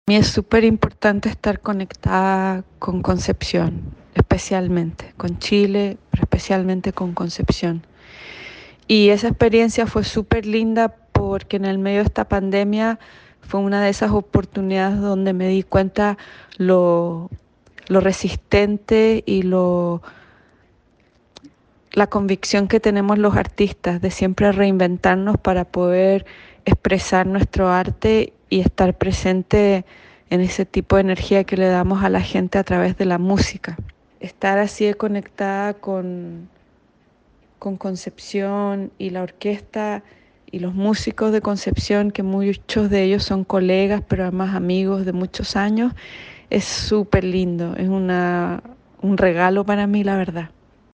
En tanto, Claudia Acuña es una cantante de jazz considerada como la más exitosa artista nacional de este género de todos los tiempos. En conversación con Radio UdeC, dijo estar muy orgullosa por la nominación y por el cariño de la gente.